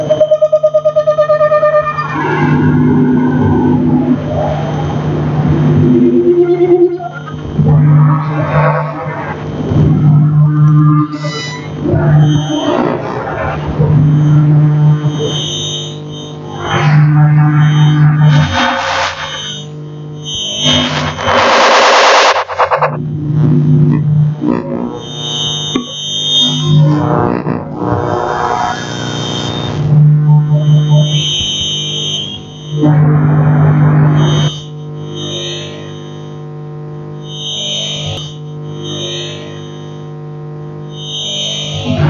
When you get close to electronic gadgets it “screams” indicating the level of the magnetic field close to the receiver.
Listen below to making electronic music using an induction loop receiver:
music-making-with-the-induction-loop-receiver.mp3